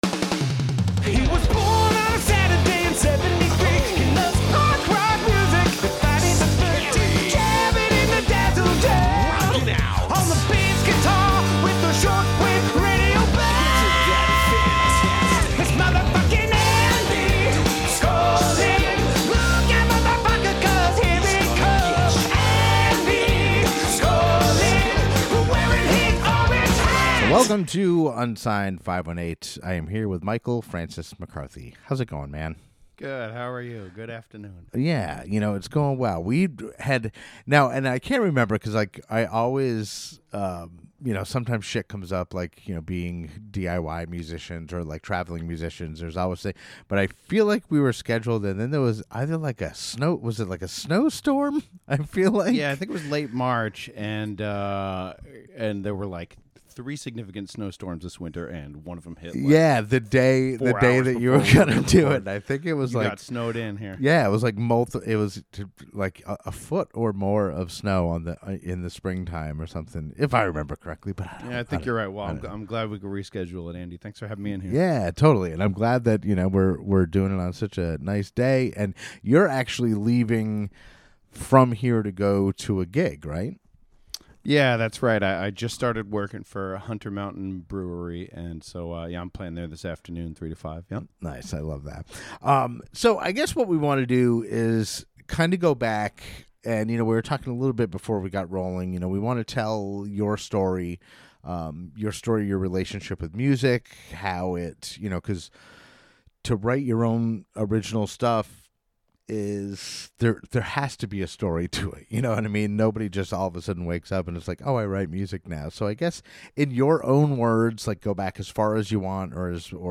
He even performs two songs live, that you can see on my YouTube channel.